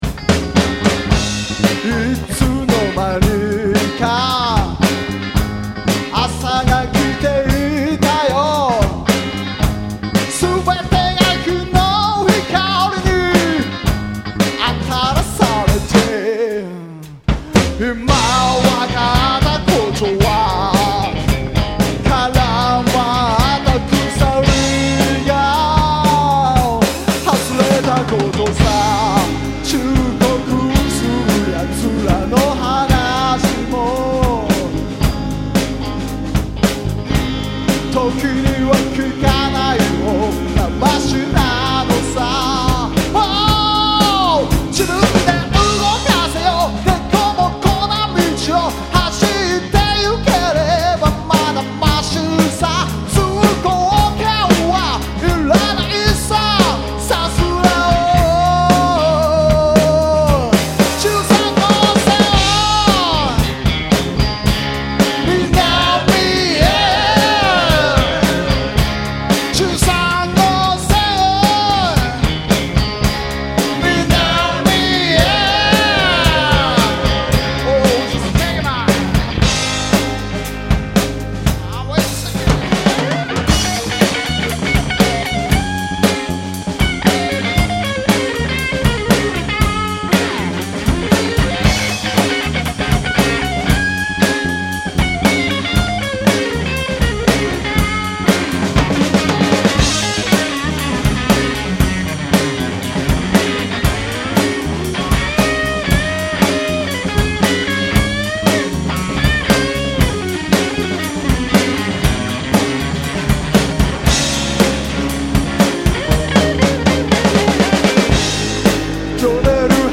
ブルースロックを基本にしながらもファンク、レゲエなどの 黒っぽい要素も多く取り入れ、
よりFunky&Powerfulなのりのいい演奏が真骨頂、また、オリジナル曲は完成度が高く、